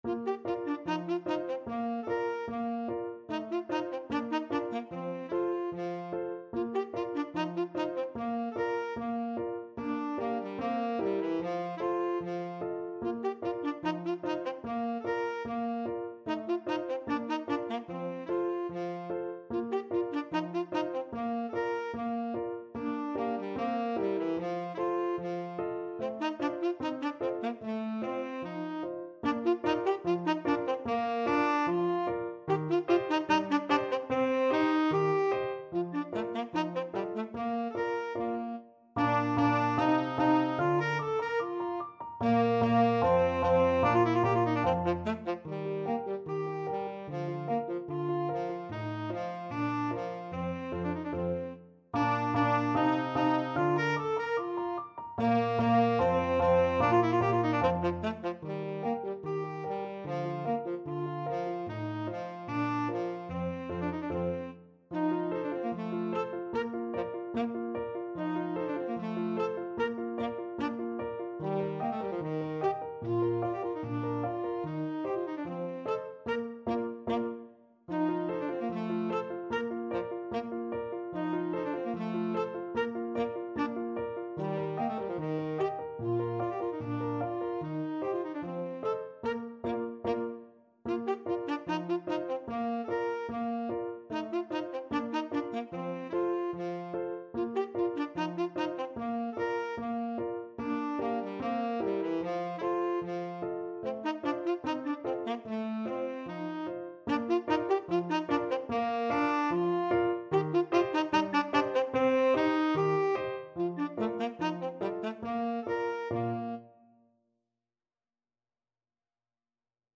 2/2 (View more 2/2 Music)
Allegretto = 74
Classical (View more Classical Tenor Saxophone Music)